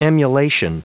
Prononciation du mot emulation en anglais (fichier audio)
Prononciation du mot : emulation